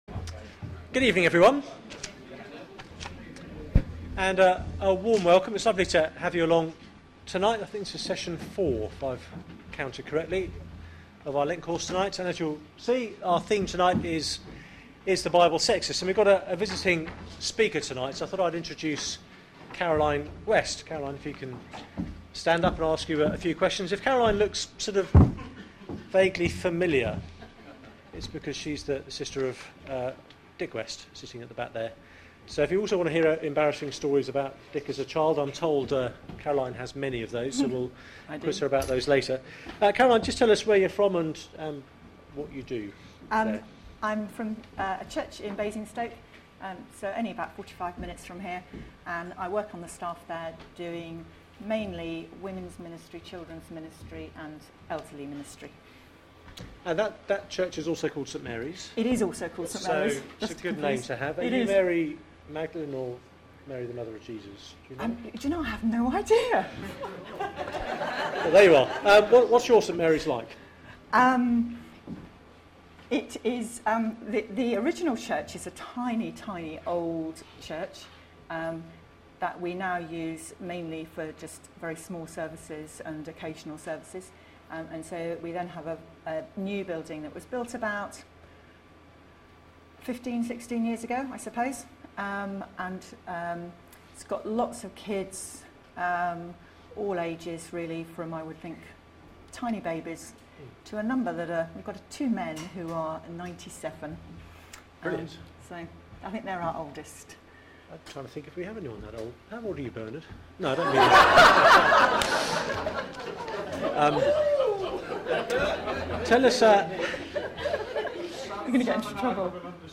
Lent Course: Is the bible sexist? Sermon Search the media library There are recordings here going back several years.